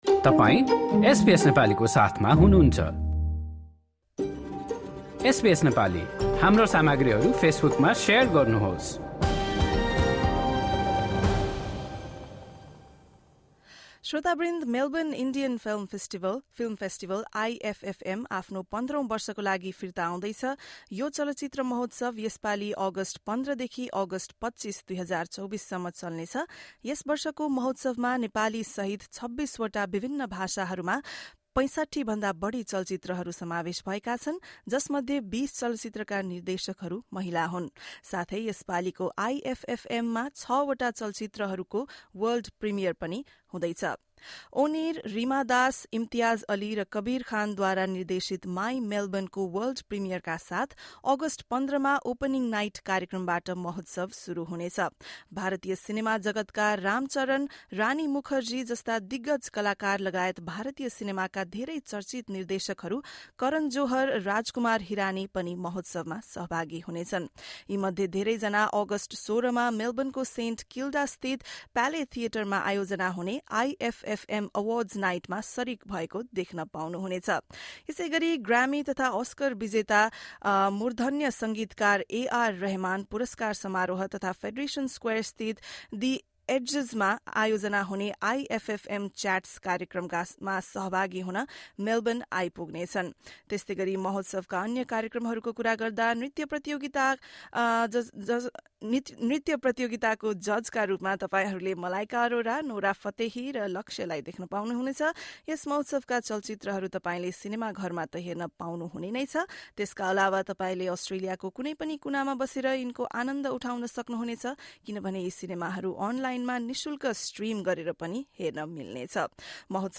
The 15th edition of the Indian Film Festival Festival of Melbourne (IFFM) takes place from Thursday, 15 August to Sunday, 25 August. This year, the festival features over 65 movies in 26 different languages, including Nepali. An event held in the capital city of Canberra welcomed Prime Minister Anthony Albanese alongside prominent figures from Indian cinema, including Producer/Director Karan Johar and Actor Rani Mukerji. Listen to this report from SBS Nepali.